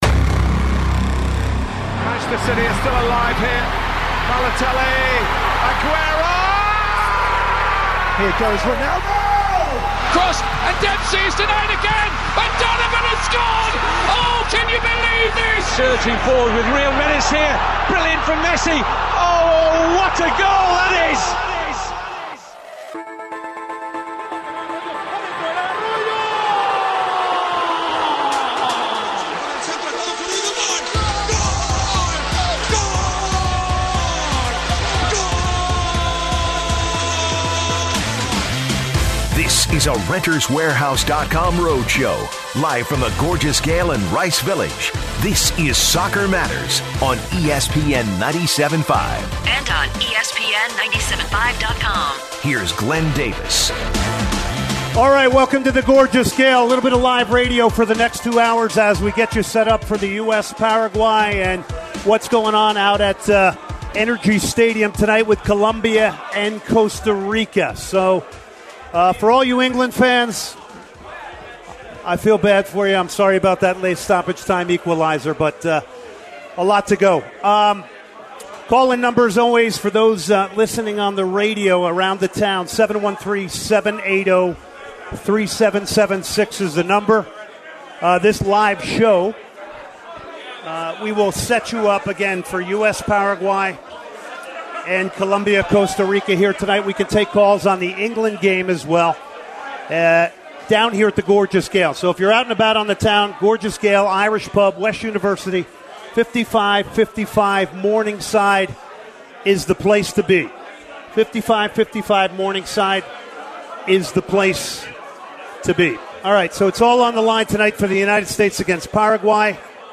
Today's show came to you live from The Gorgeous Gael